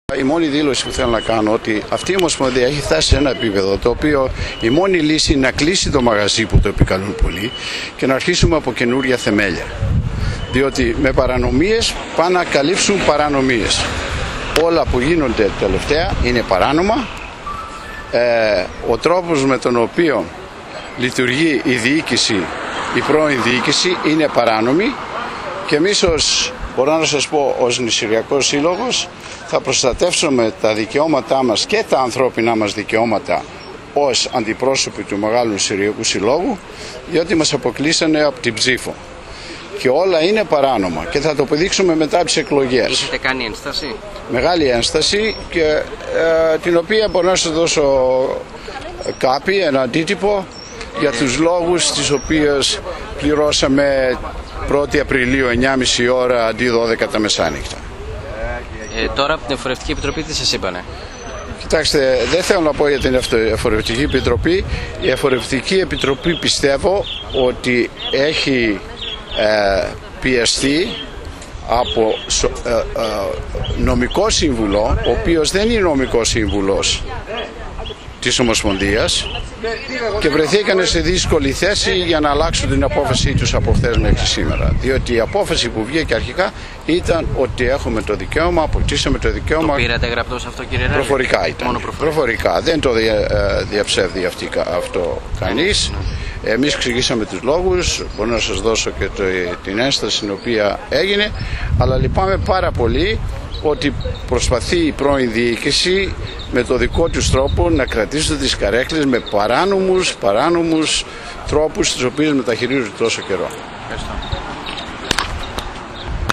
ΑΚΟΥΣΤΕ LIVE ΟΛΟΚΛΗΡΗ ΤΗΝ ΔΗΛΩΣΗ